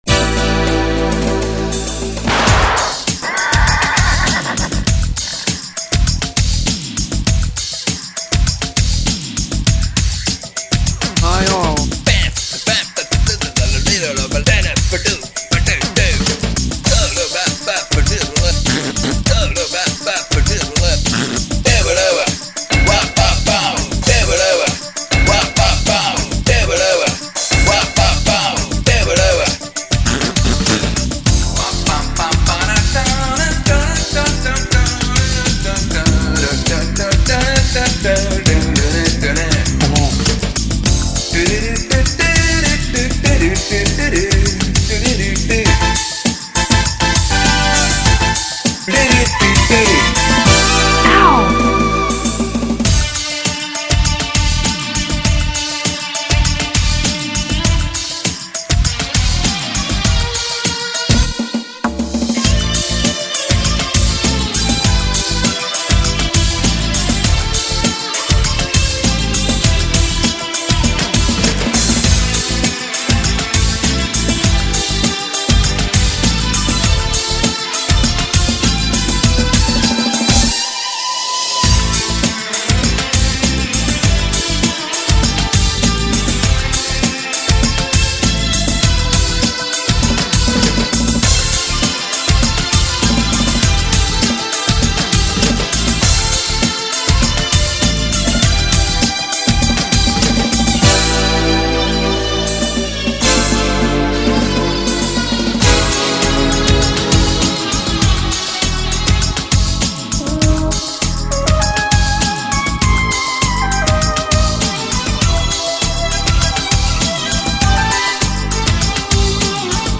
Ke∩ som ju skusmo vyrßbal, spev som nahodil len tak.
Obe pesniΦky aj ostatnΘ ukß╛ky v tomto webovom hniezdoΦku vznikli na be╛nom poΦφtaΦi s kartou AWE 32 + 8mega RAM (V tom Φase okolo 9000.- Sk s DPH) a prφdavn²m wavetable modulom Yamaha DB50XG (v tom Φase okolo 6000.- Sk s DPH) a╛ po mastering st⌠p na CD.